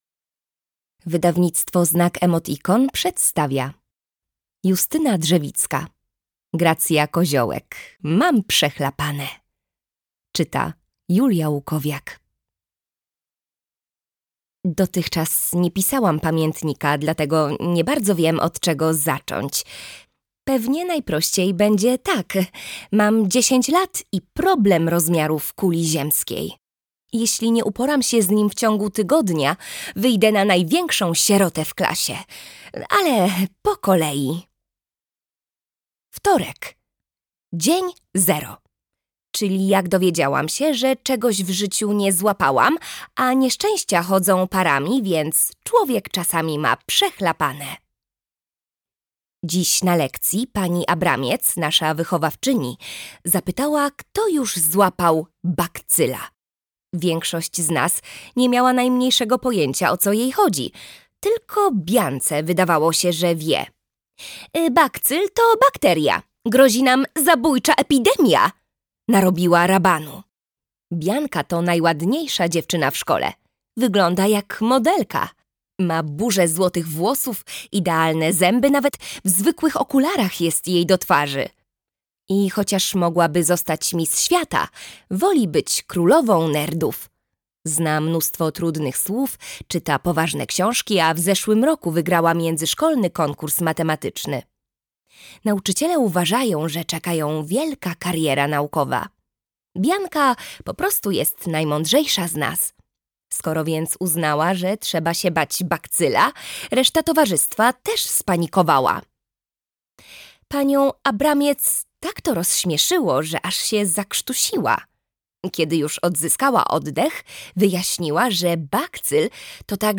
Gracja Koziołek. Mam przechlapane - Justyna Drzewicka - audiobook + książka